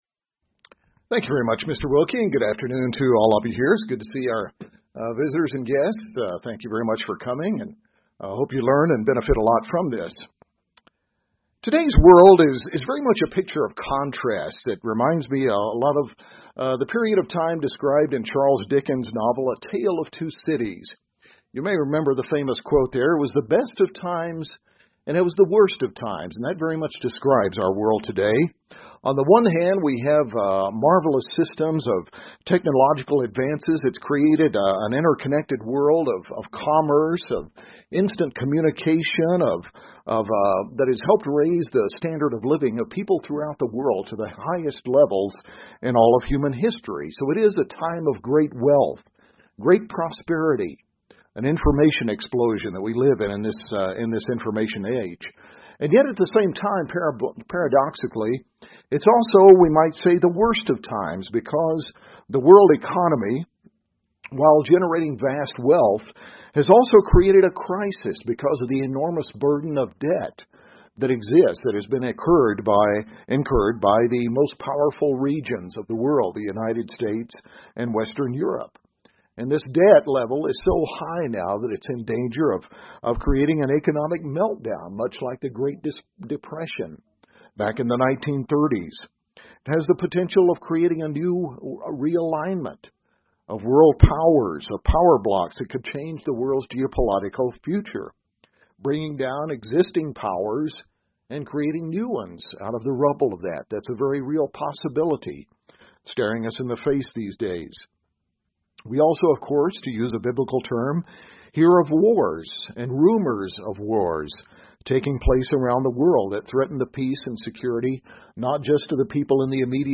Learn more in this Kingdom of God seminar.